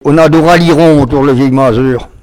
Localisation Olonne-sur-Mer
locutions vernaculaires
Catégorie Locution